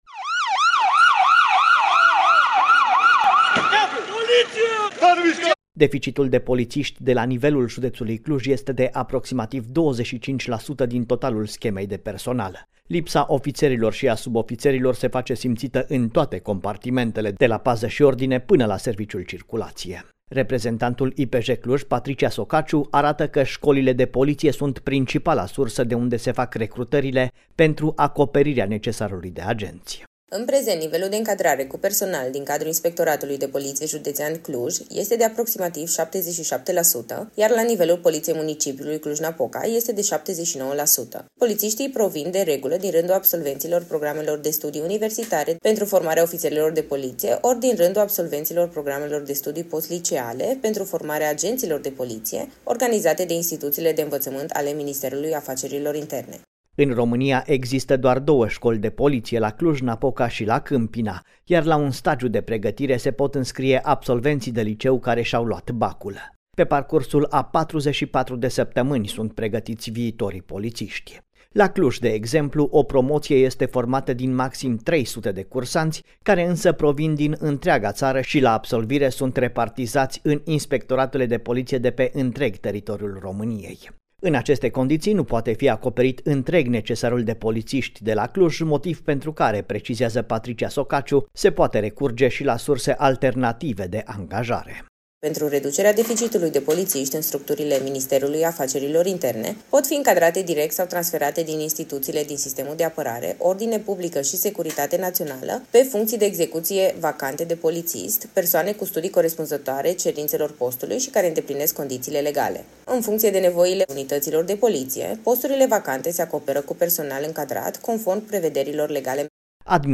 reportaj-deficit-politisti.mp3